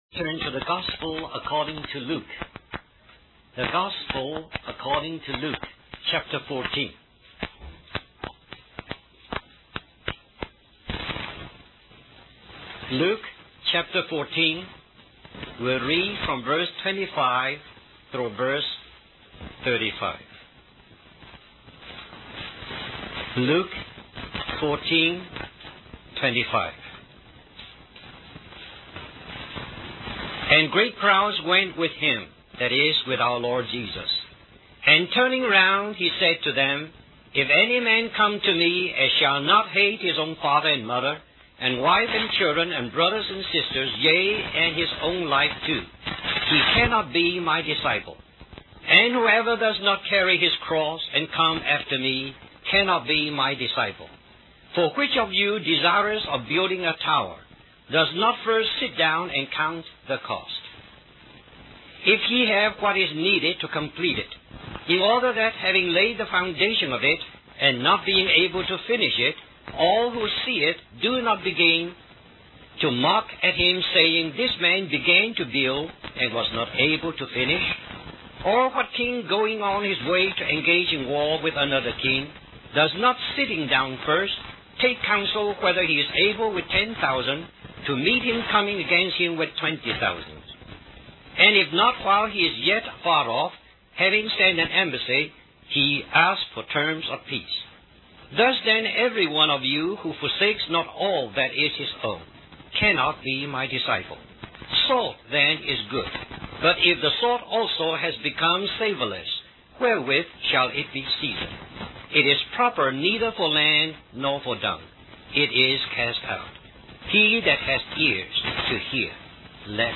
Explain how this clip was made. Richmond, Virginia, US